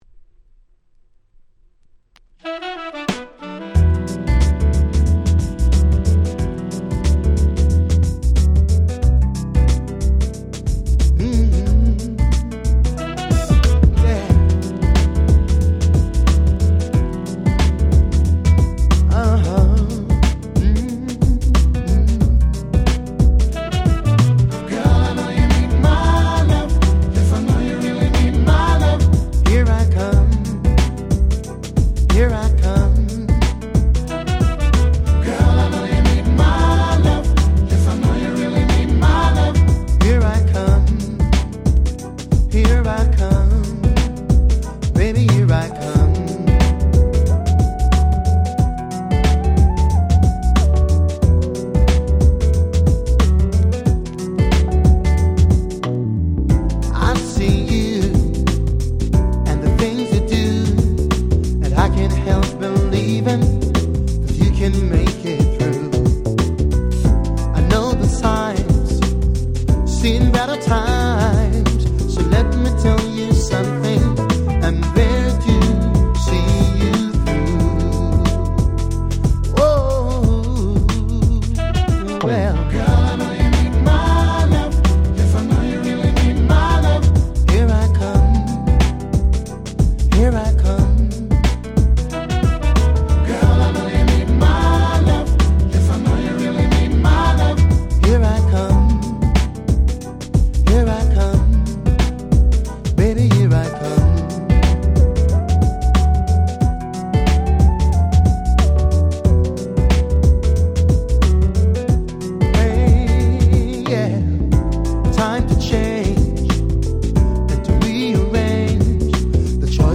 97' Nice R&B / Reggae !!
これはもうレゲエと言うよりはR&Bです。
JazzyでムーディーなBeatに彼の甘い歌声がもう100点満点！！